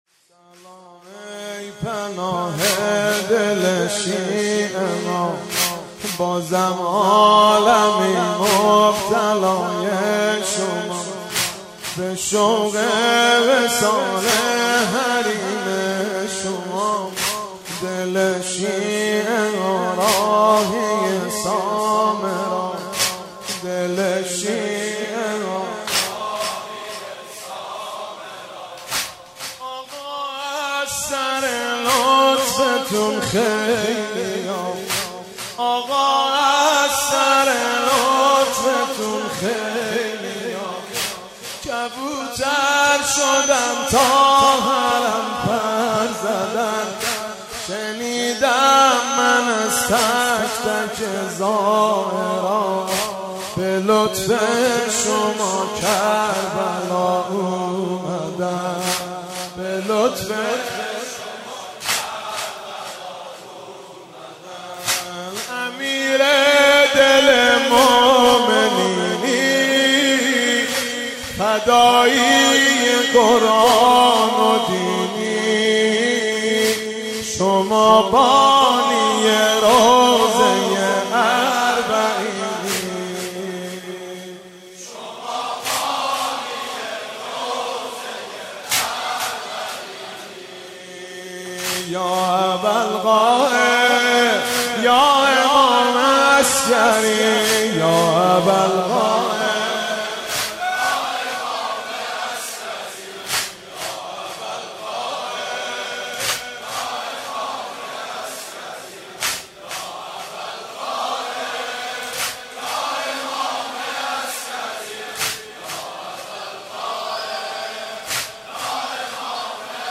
سبک مداحی شورانگیز